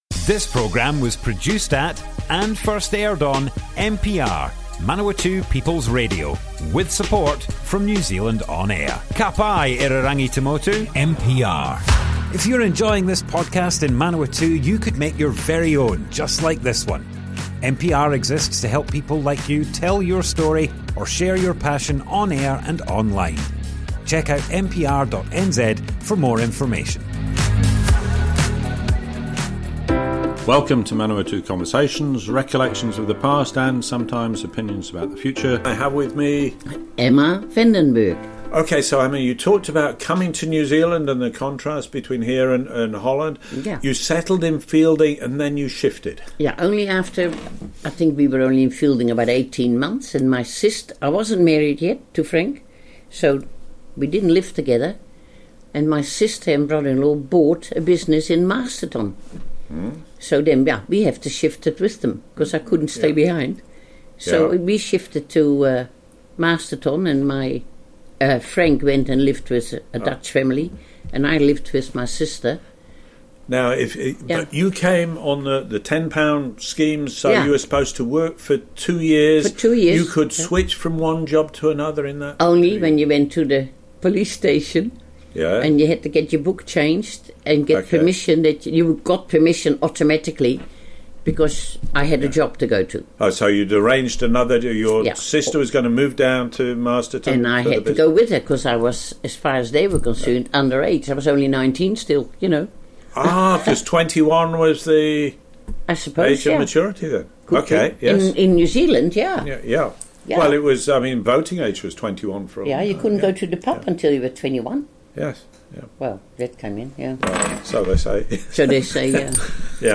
Manawatu Conversations More Info → Description Broadcast on Manawatu People's Radio, 12th August 2025.
oral history